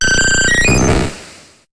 Cri d'Aquali dans Pokémon HOME.